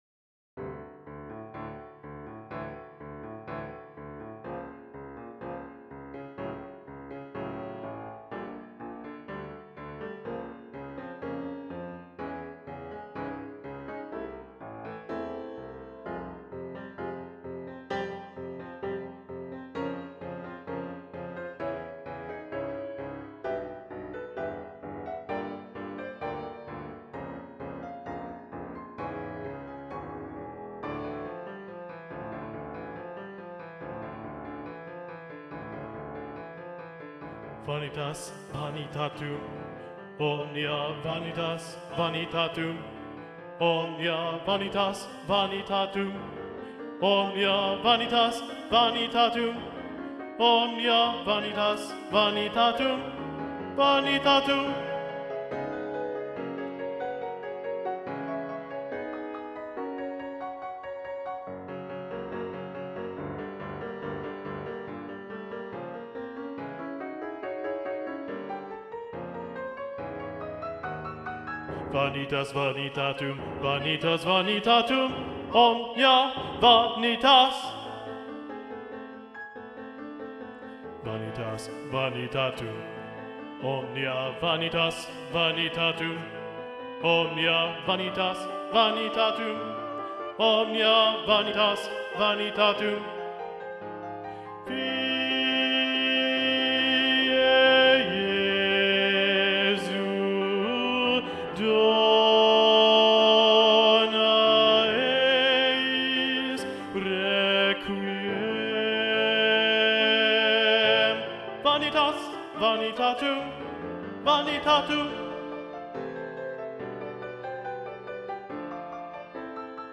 Video Only: Vanitas Vanitatum - Bass 1 Predominant